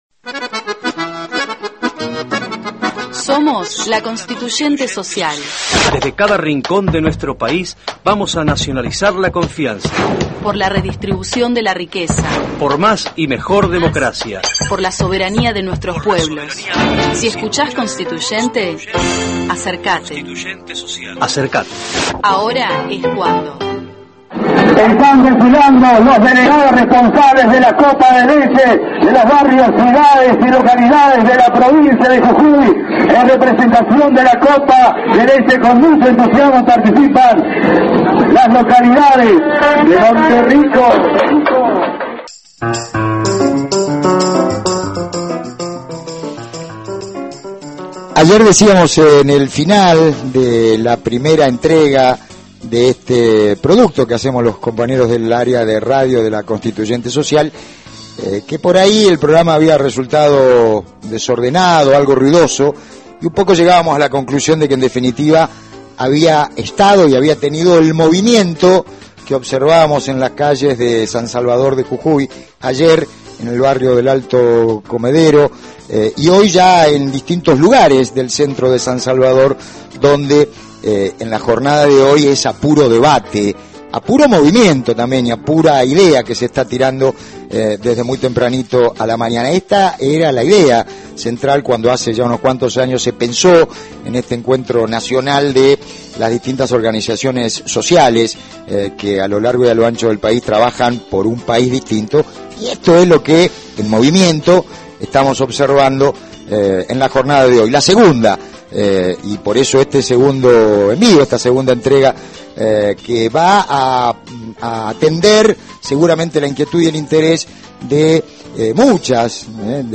Escuchá el segundo programa de la Constituyente Social, emitido el sábado 25 de octubre desde la sede de la organización barrial Tupac Amaru y el Estadio la Tablada, San Salvador de Jujuy, y retransmitido a través del Foro Argentino de Radios Comunitarias (Farco) y Agencia Pulsar a radios comunitarias de todo el país y América Latina. documentos asociados podes bajar este archivo Programa Constituyente Social Sabado 25 (MP3 - 14.7 MB)